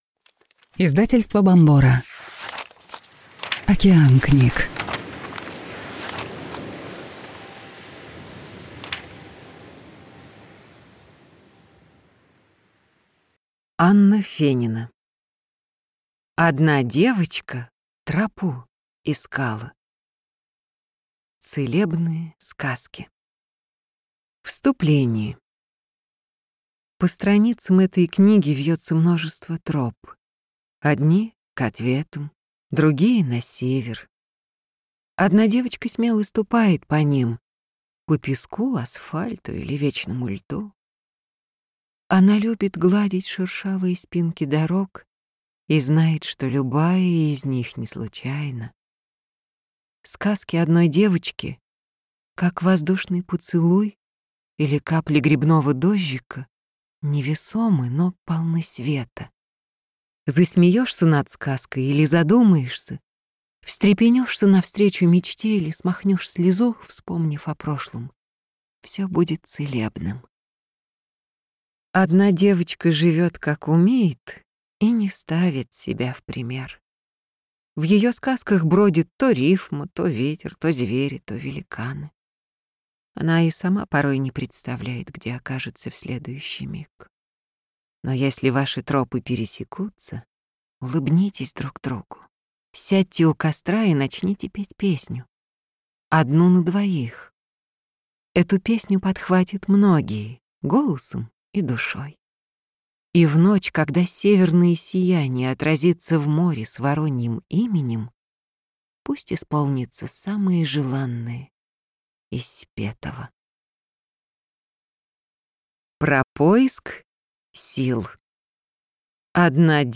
Аудиокнига Одна девочка тропу искала | Библиотека аудиокниг